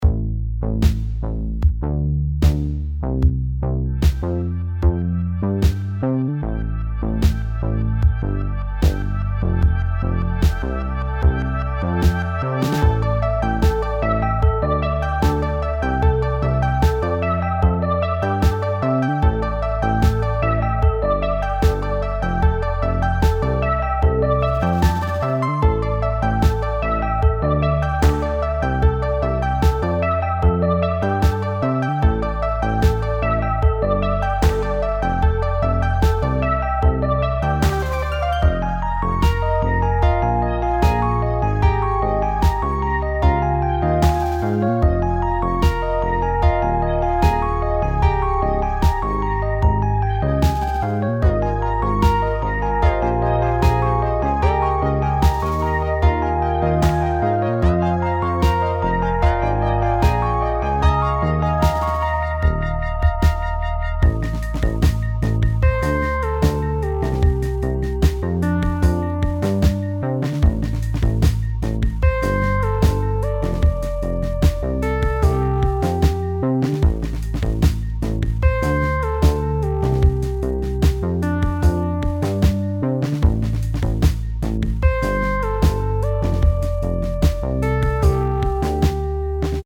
clicks.ogg